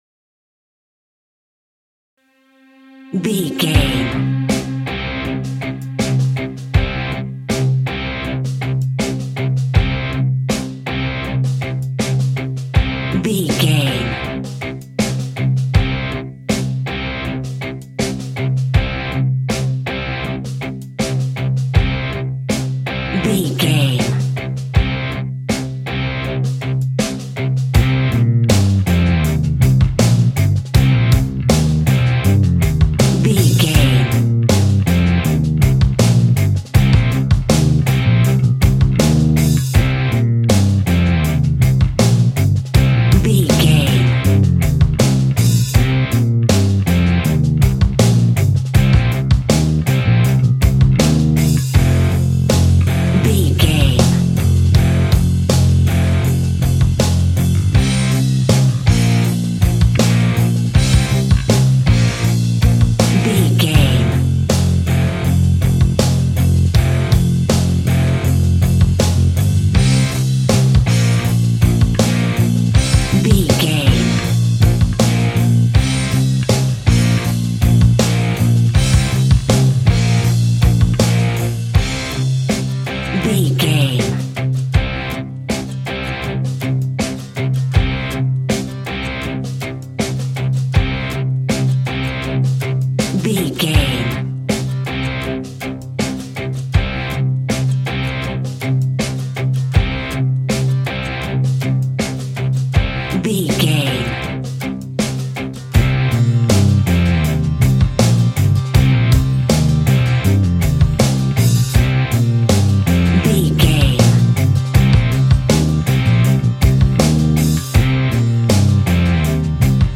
Ionian/Major
hard
groovy
powerful
electric guitar
bass guitar
drums
organ